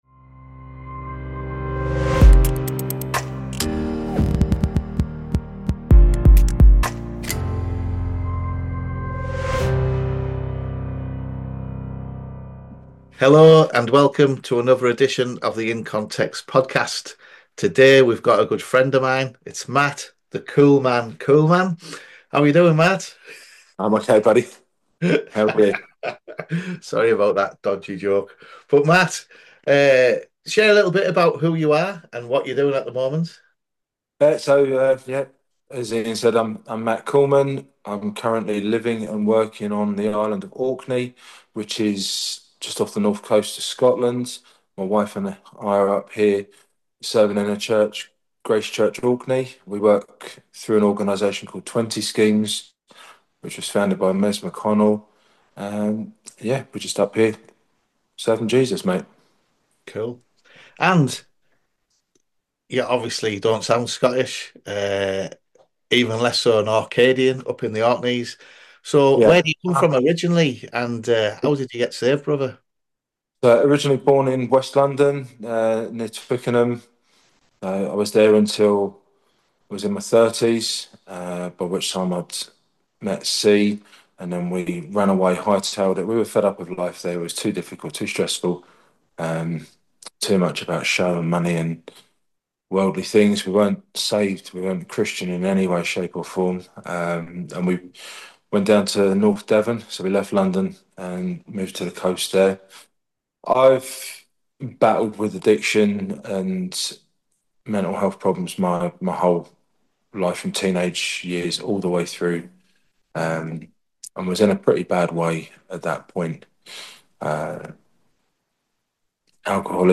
It is a thoughtful and encouraging conversation about grace, perseverance, and gospel ministry in a challenging place.